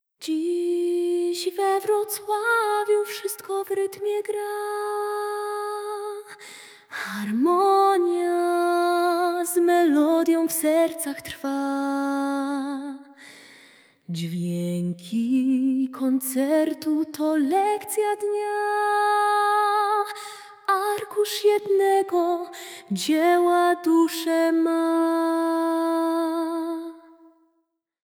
PIOSENKA NR 1 (dla kat. młodzieżowej)
Dzis we Wroclawiu - a capella 26sek.mp3